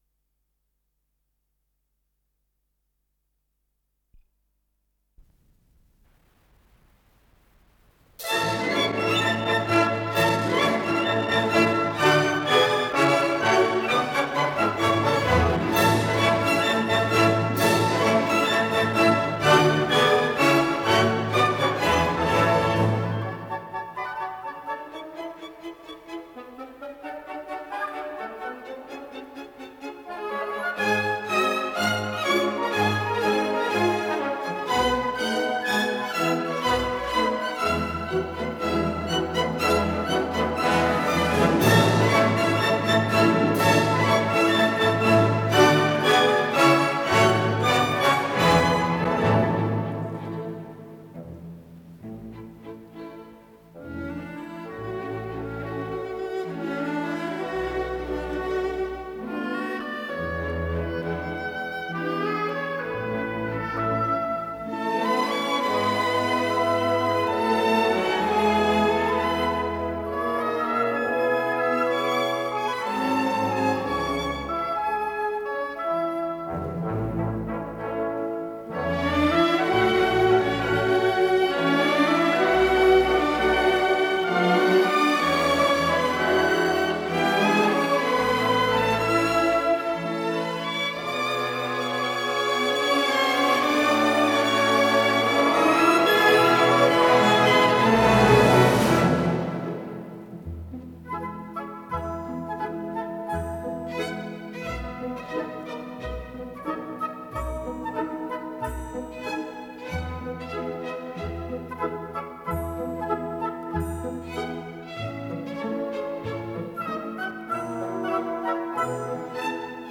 с профессиональной магнитной ленты
ИсполнителиОркестр Государственного Академического Большого театра СССР
ВариантДубль моно